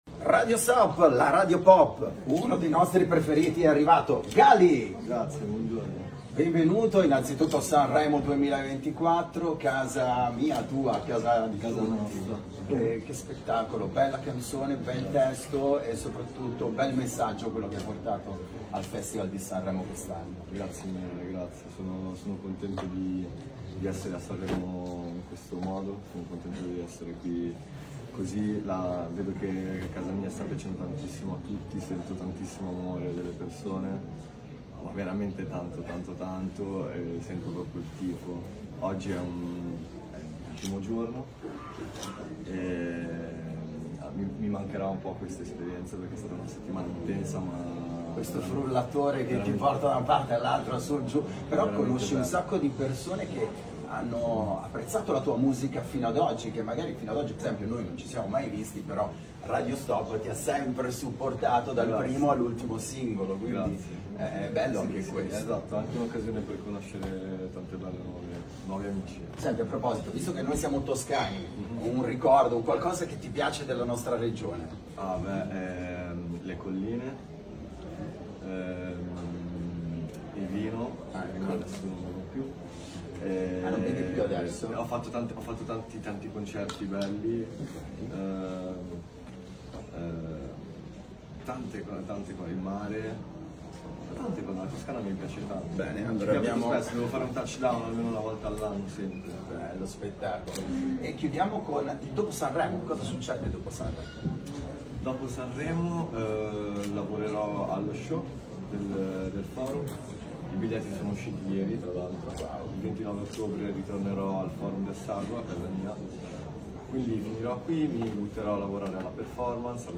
Festival di Sanremo con Radio Stop!
Radio Stop – Intervista a GHALI
Intervista-a-GHALI.mp3